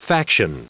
Prononciation du mot faction en anglais (fichier audio)